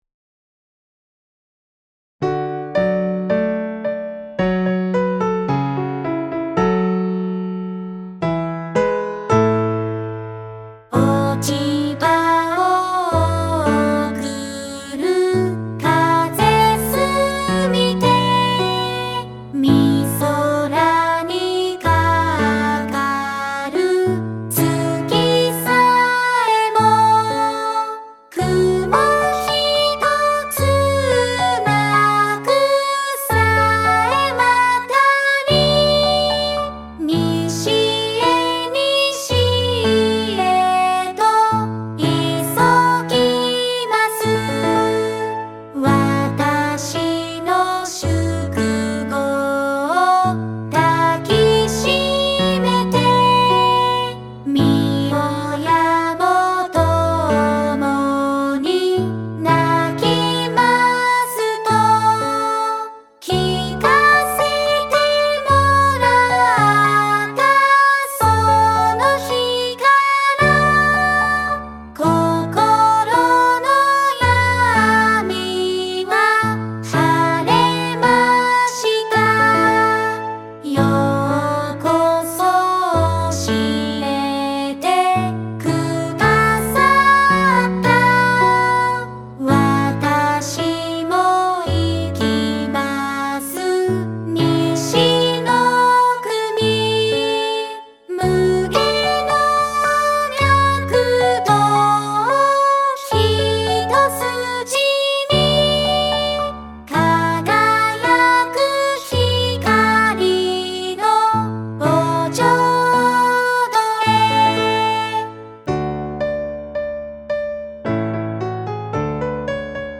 西の国（友森とし子さんの詩一部 唄：巡音ルカ・GUMI）